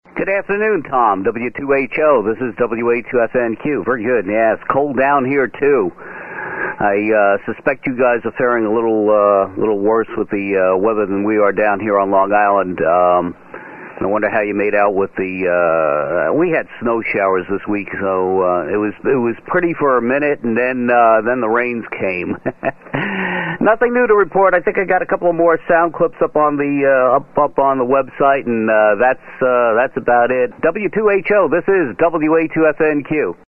These are some audio samples of the Orange County Amateur Radio Club Net which meets Sundays at 12 noon on 3.920 MHz LSB.
Antenna: 75 meter dipole/Johnson Matchbox
Radio: ICOM IC745 transceiver/SB 200 amplifier
Signals are typically S5 to 10 over S9